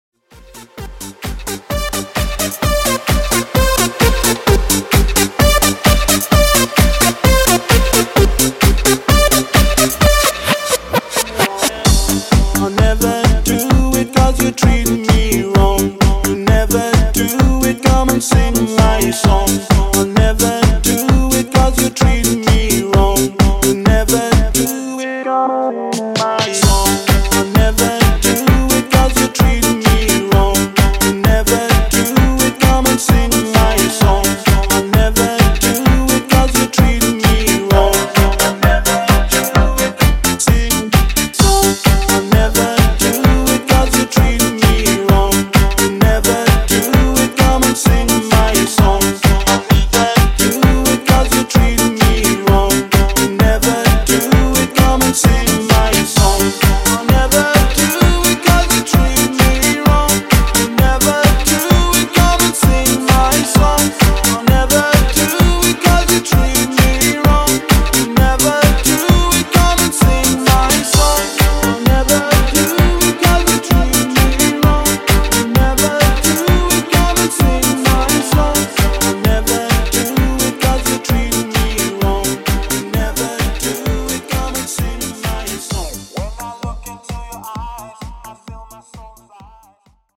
90s Dance Redrum)Date Added